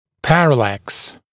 [‘pærəlæks][‘пэрэлэкс]параллакс